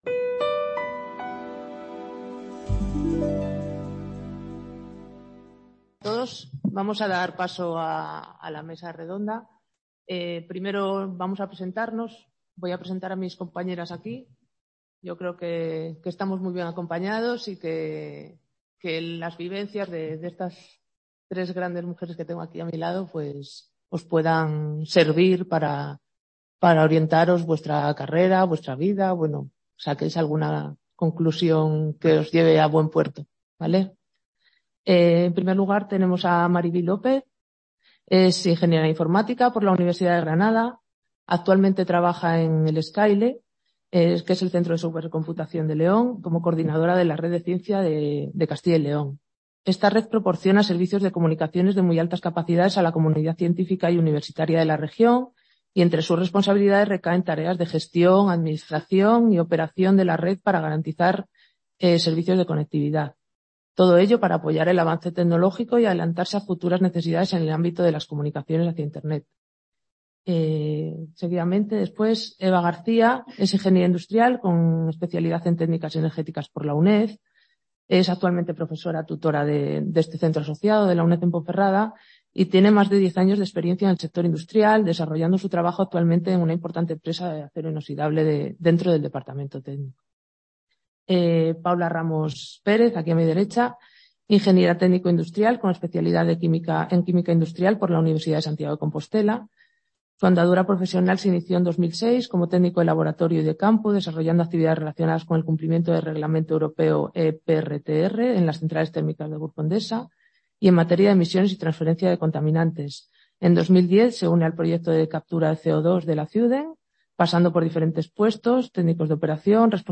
Mesa Redonda